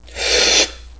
assets/common/sounds/player/inhale.wav at main
inhale.wav